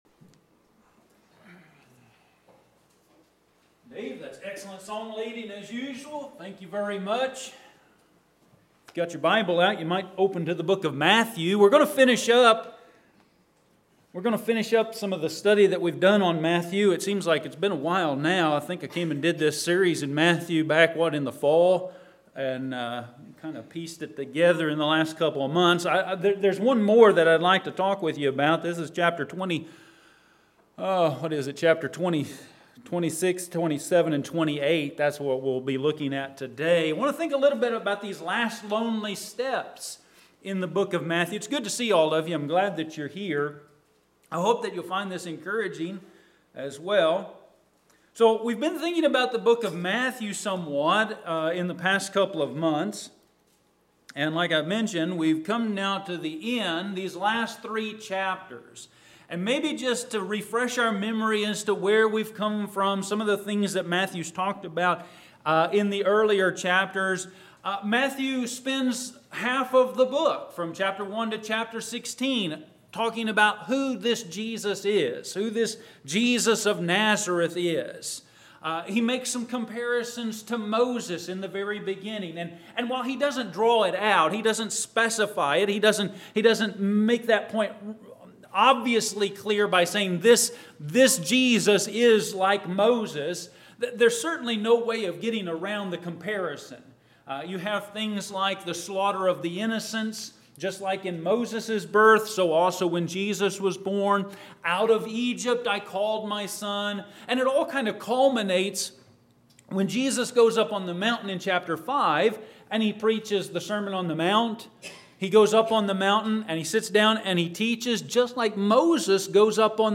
Sermons - Olney Church of Christ
Service: Sunday AM